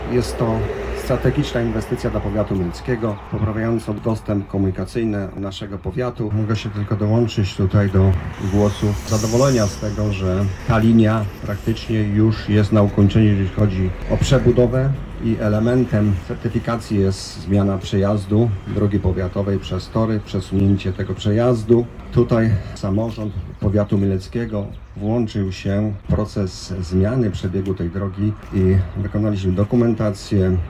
Mówi Stanisław Lonczak, starosta mielecki.